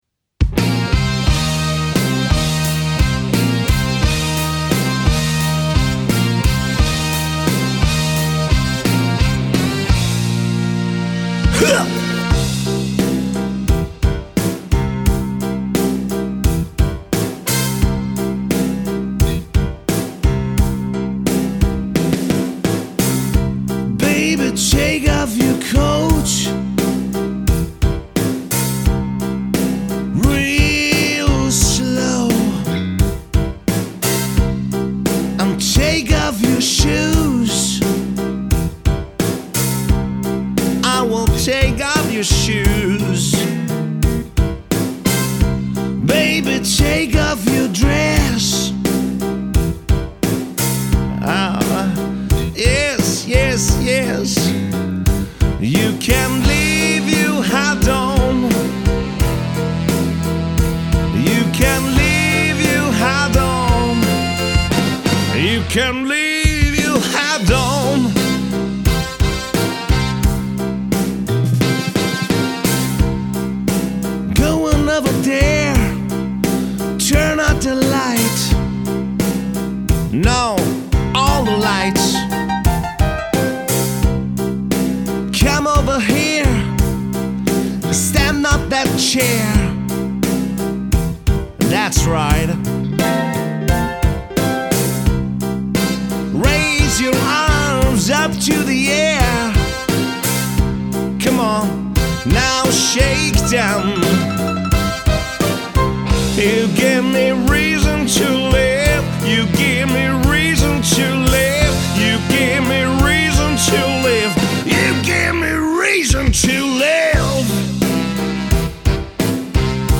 Partyband
Demosongs